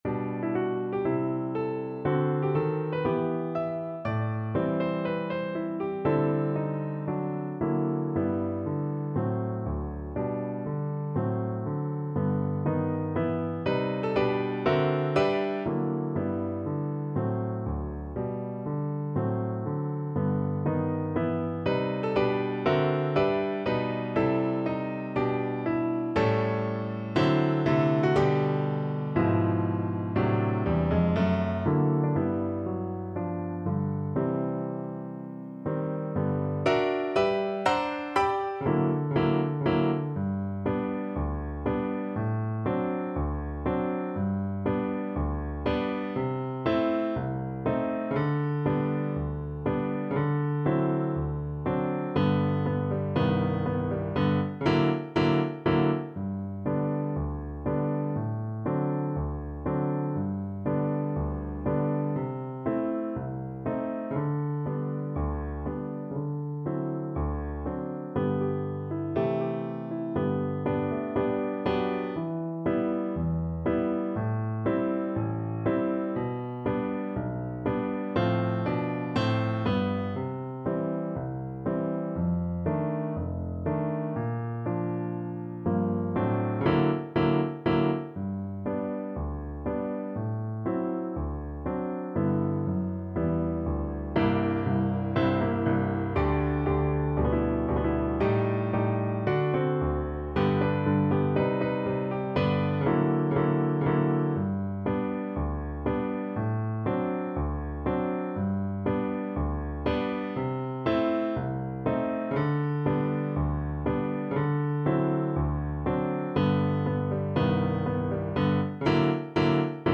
~ = 120 Moderato